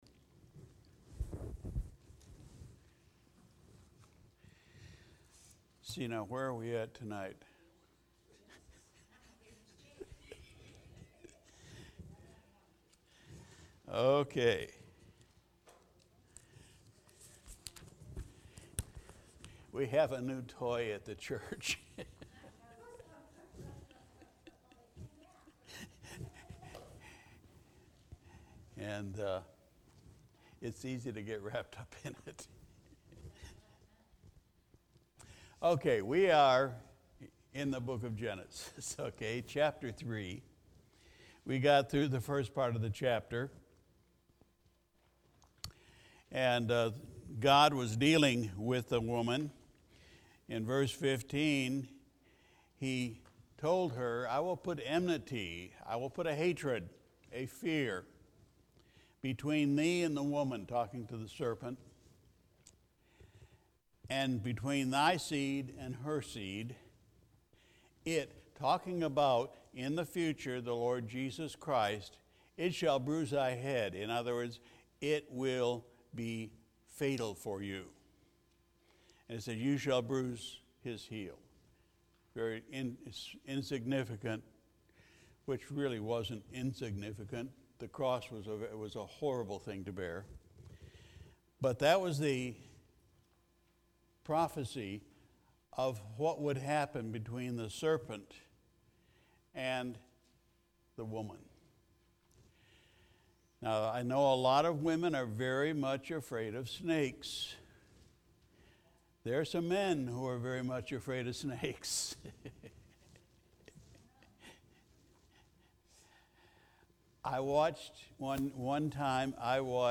March 14 2021 Sunday Evening Service We continued our study in the Book of Genesis